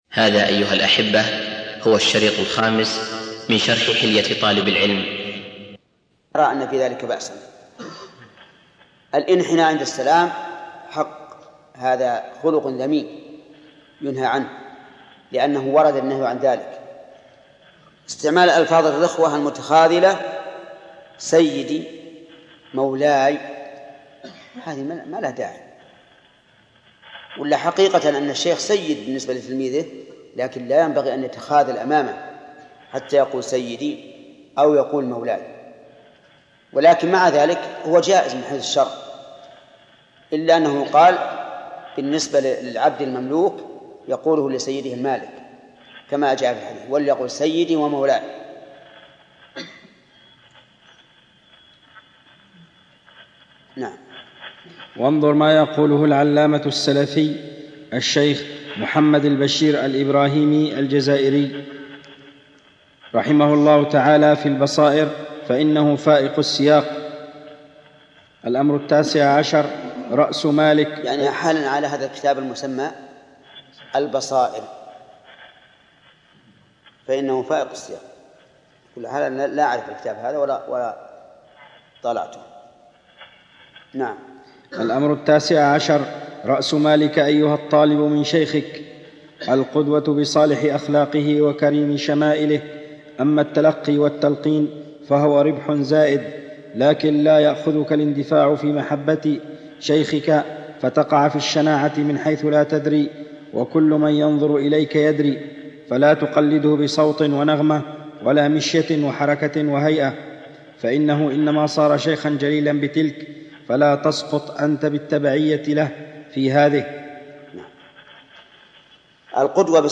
الدرس الخامس - شرح كتاب حلية طالب العلم - فضيلة الشيخ محمد بن صالح العثيمين رحمه الله